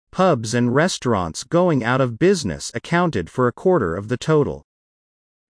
【ややスロー・スピード】
大きな文字:強勢のある母音
下線:音の連結　( ):子音の脱落